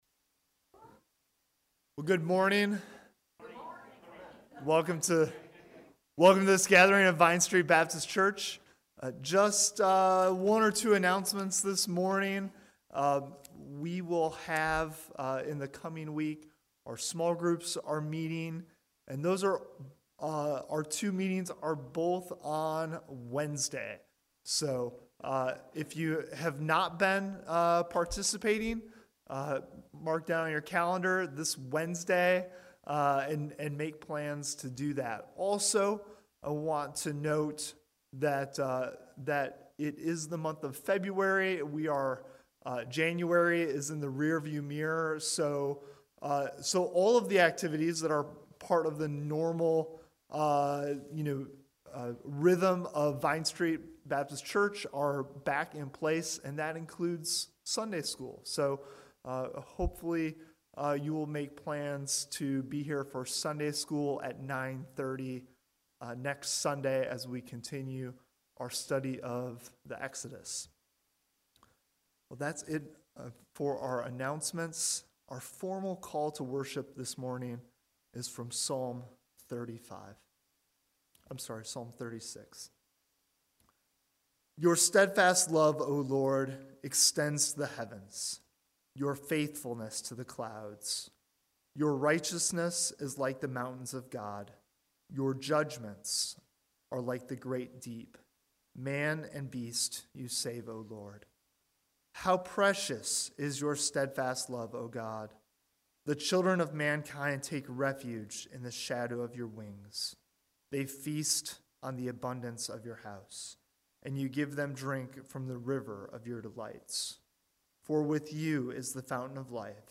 February 13 Worship Audio – Full Service